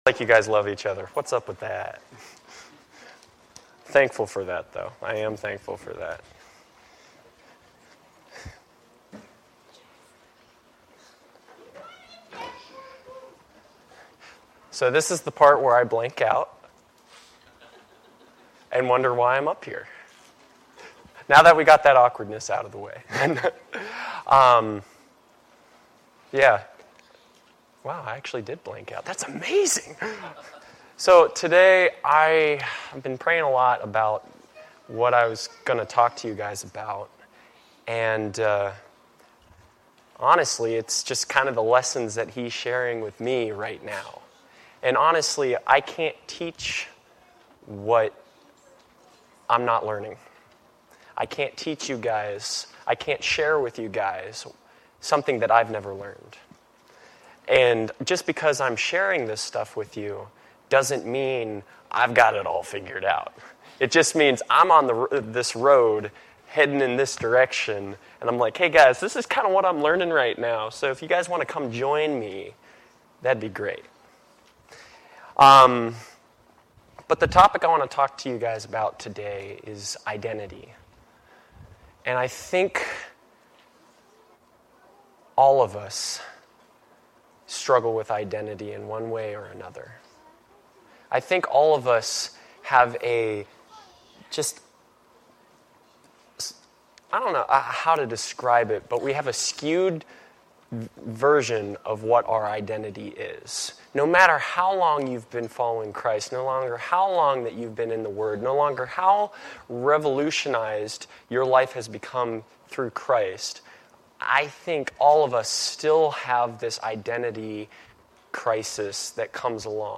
This is the main Sunday Service for Christ Connection Church